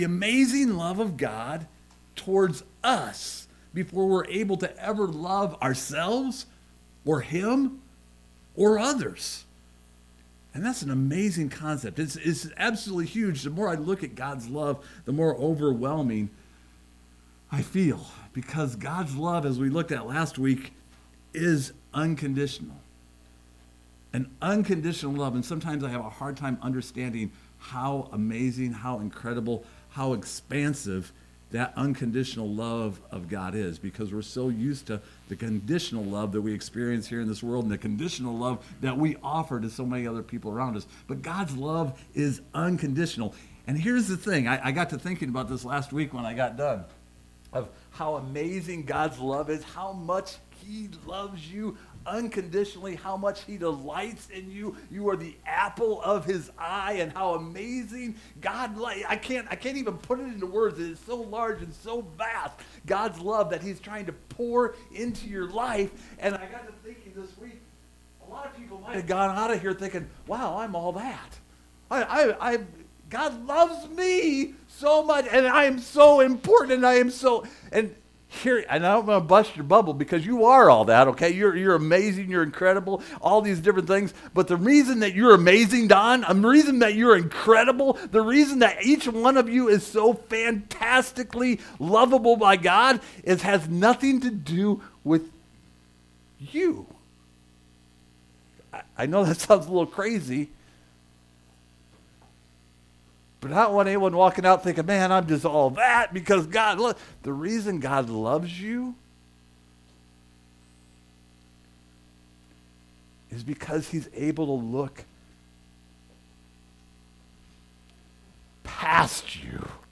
Psalm 139:1-10 Service Type: Sunday Morning Open yourself up for God's Plan.